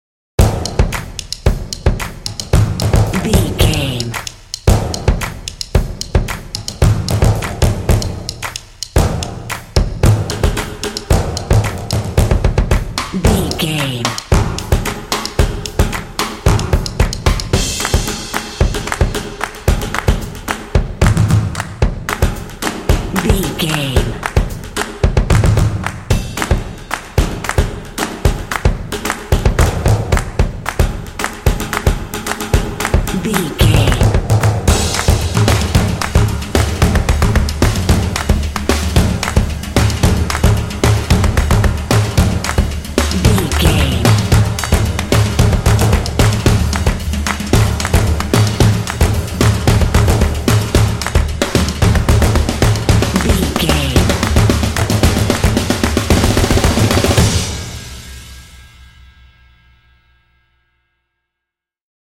Epic / Action
Atonal
confident
tension
percussion
drumline